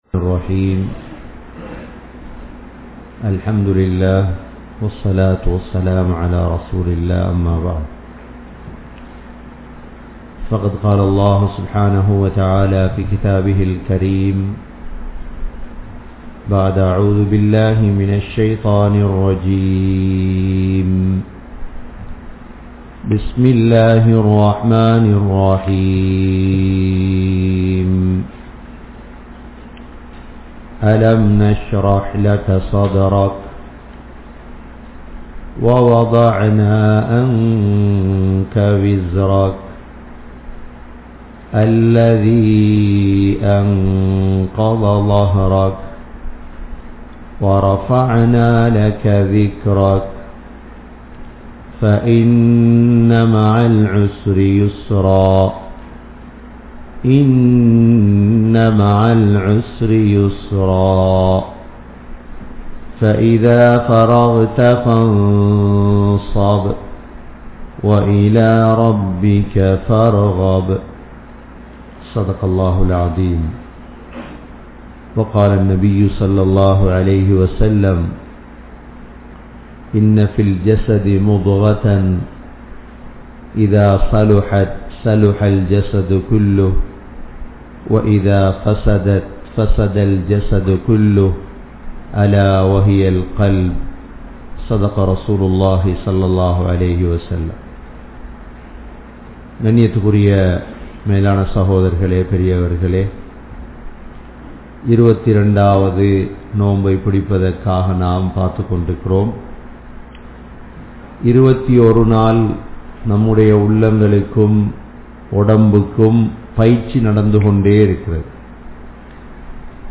Ullaththai Thooimaiyaakkungal (உள்ளத்தை தூய்மையாக்குங்கள்) | Audio Bayans | All Ceylon Muslim Youth Community | Addalaichenai
Canada, Toronto, Thaqwa Masjidh